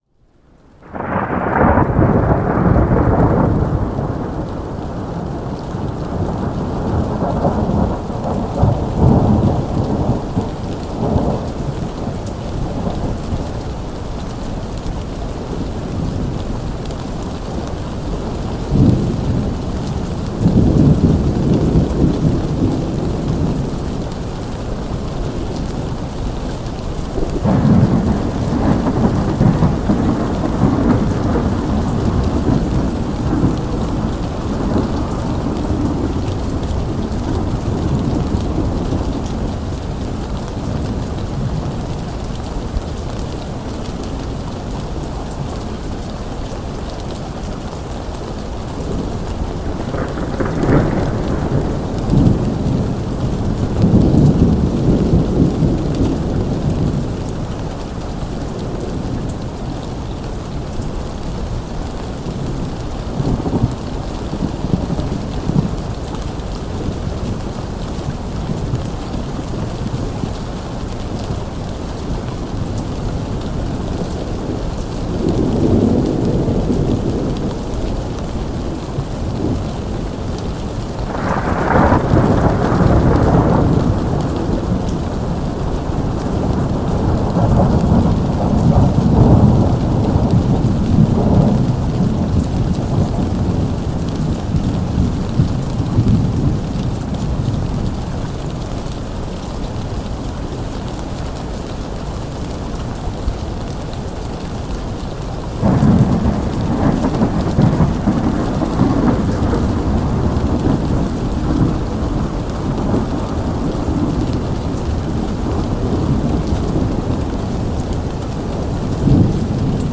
Pluie soutenue sur cabane isolée générant une atmosphère de retrait profond